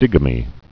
(dĭgə-mē)